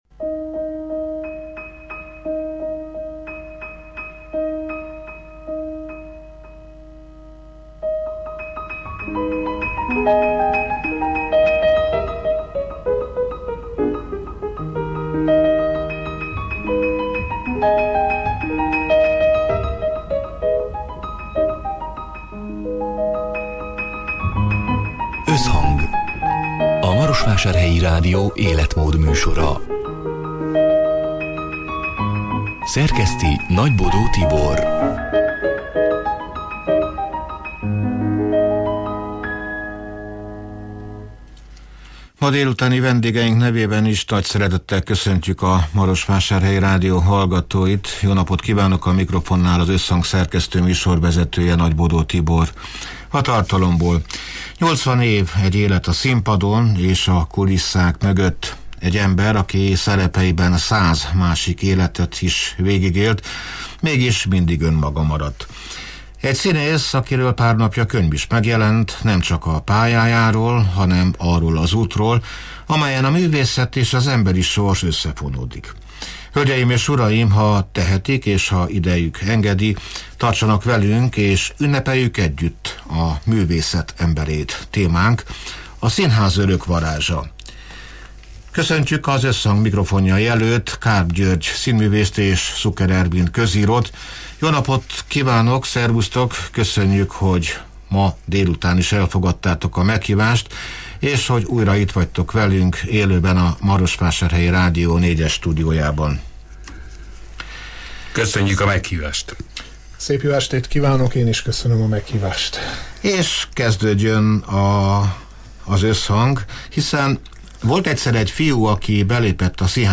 A Marosvásárhelyi Rádió Összhang (elhangzott: 2025. október 22-én, szerdán délután hat órától élőben) c. műsorának hanganyaga: